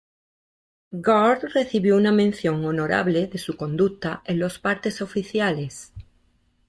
ho‧no‧ra‧ble
/onoˈɾable/